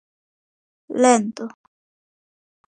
/ˈlento̝/